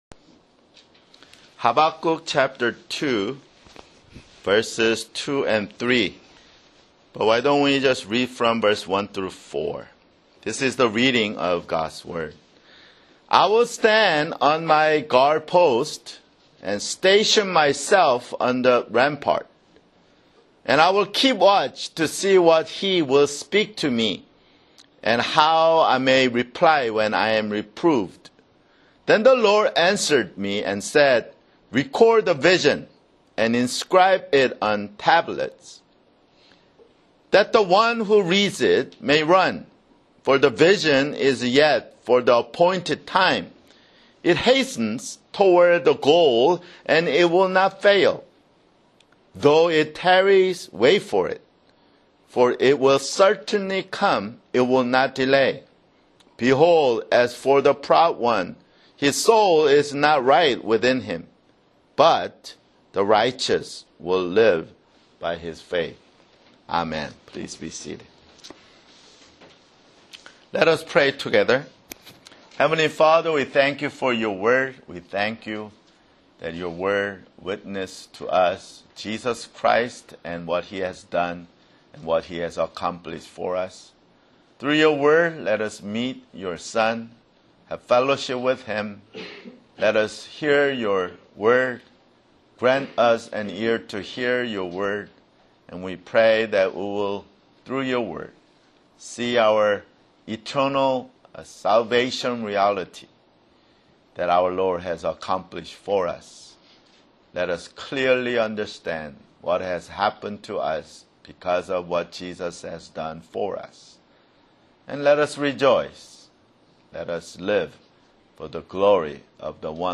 [Sermon] Habakkuk (7)